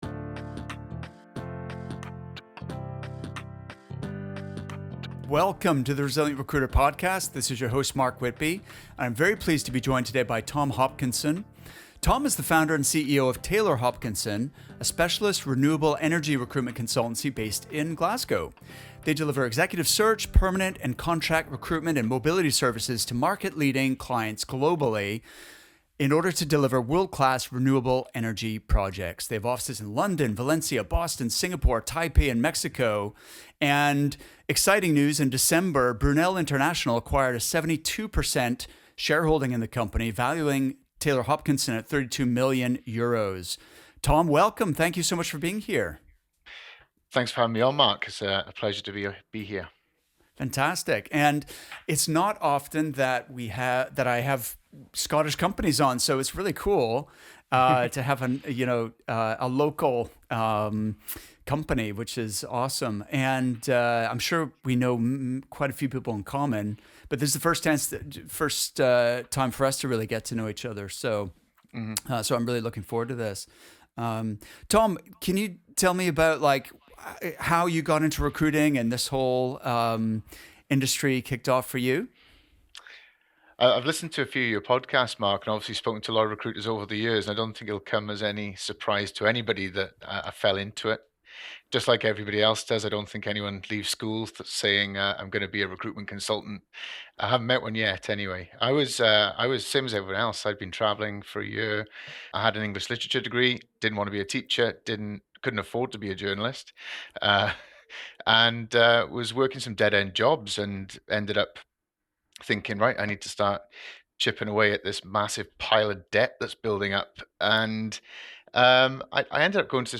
31 Jan 2022 Notes Many founders dream of selling their business one day. In this interview, you’ll hear from someone who’s actually done it.